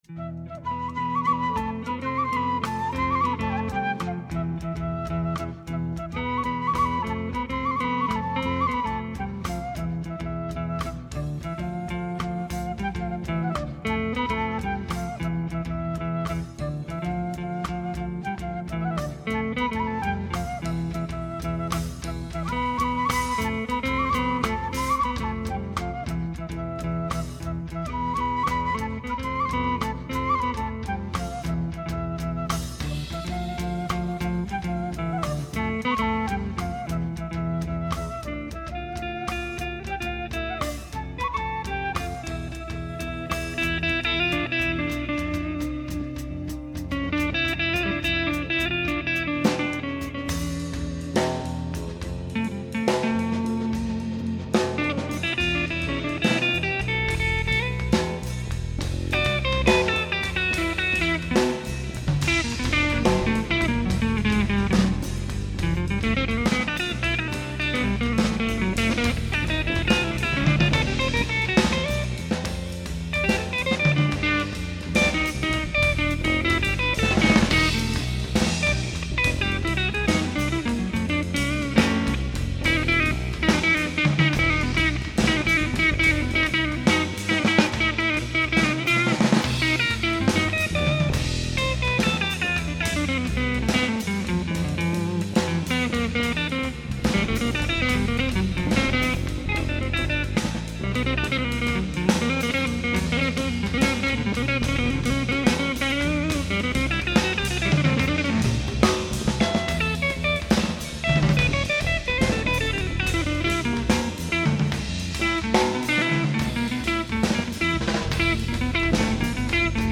trad. Rom
Etno-jazz flauto tr., chitarra, basso, tamburi